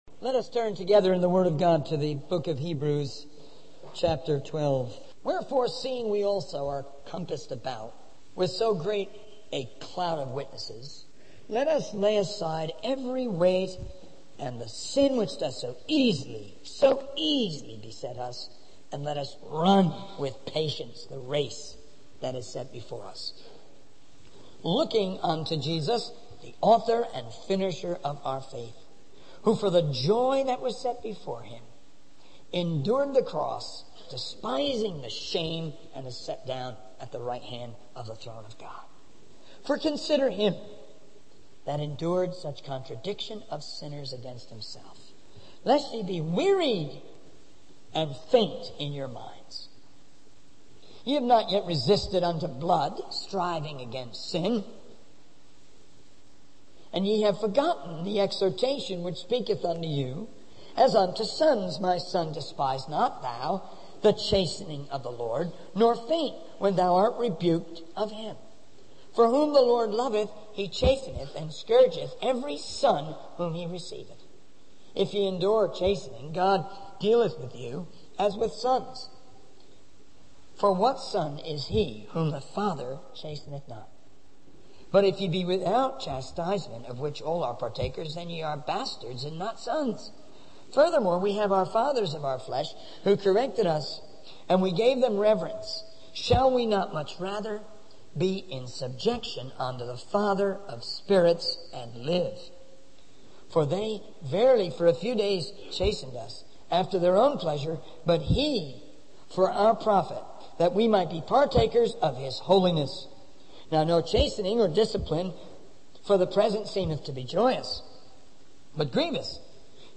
In this sermon, the speaker emphasizes the importance of evangelism and the various ways it can be done. He shares the success of a recent evangelistic event in Germany where thousands of people came and hundreds professed faith in Jesus Christ.